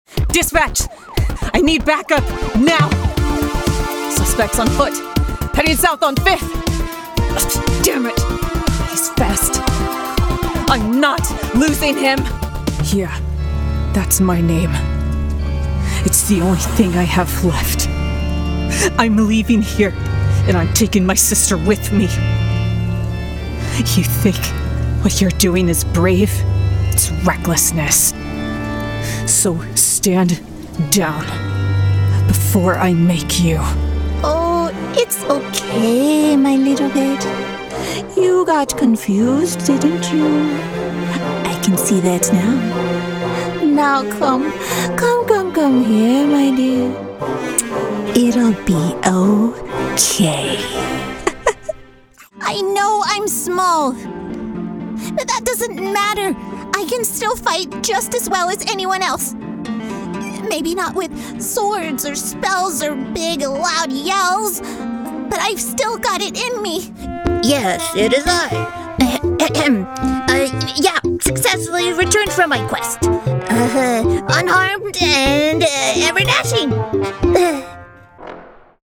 Dynamic. Adaptable. Unforgettable.
• High-Quality Audio - Professional setup, clear sound, ready for production.
• Emotion Focus - My performances convey raw vulnerability, sly mischief, quiet strength, or electrifying passion, rooted in truth and authenticity.
• Range - From cool, mysterious tones to fiery, high-energy deliveries, I adapt effortlessly to suit your project's needs.